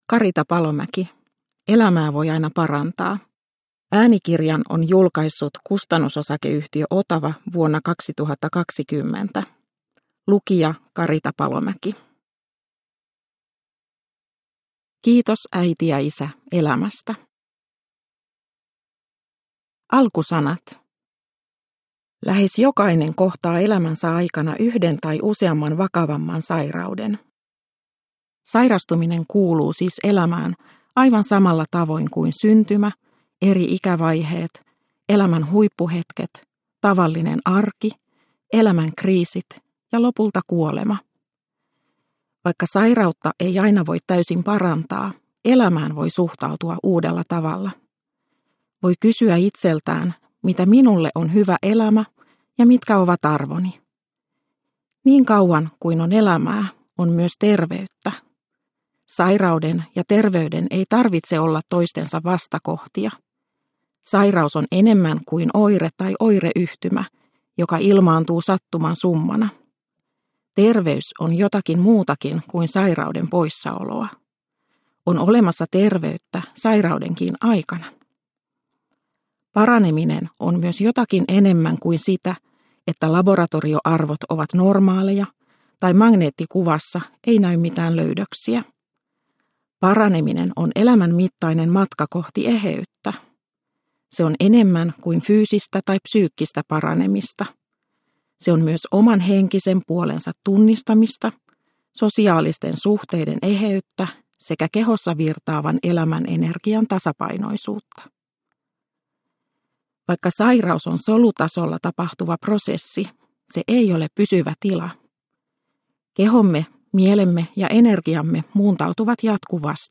Elämää voi aina parantaa – Ljudbok – Laddas ner